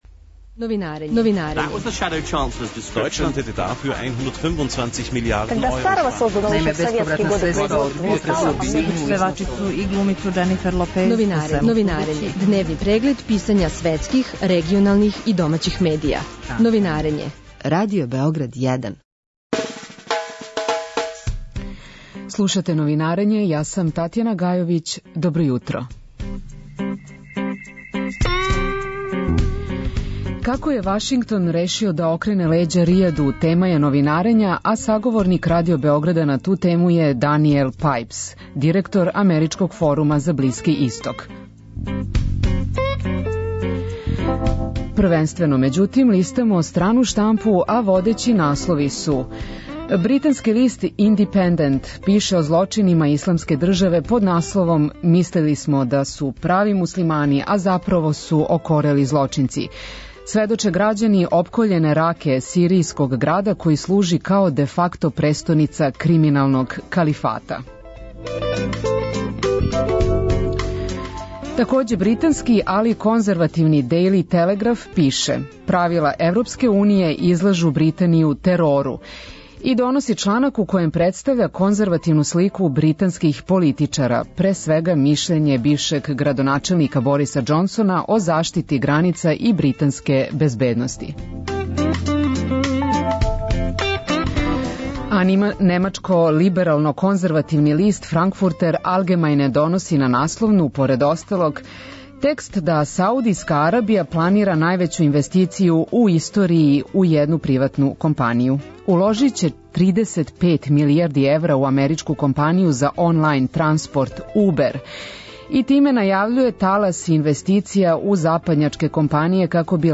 Анализу односа две државе излаже саговорник Радио Београда 1 Денијел Пајпс, директор америчког Форума за Блиски исток.